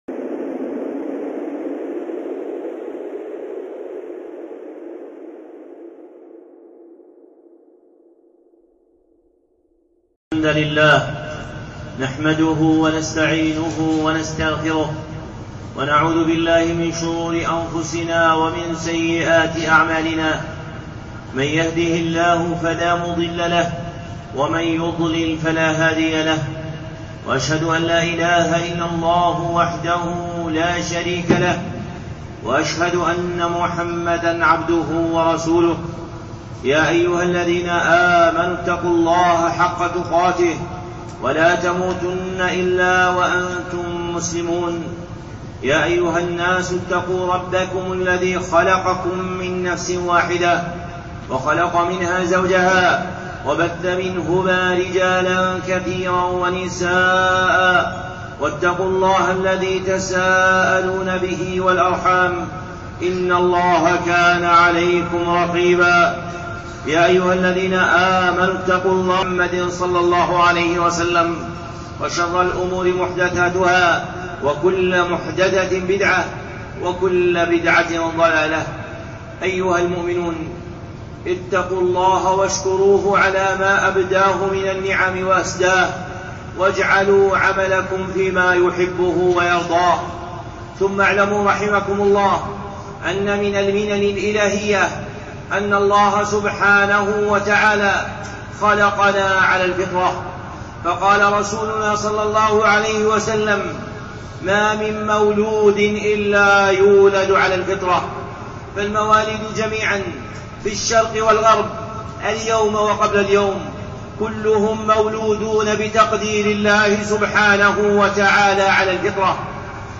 خطبة (الفطرة باطنا وظاهرا)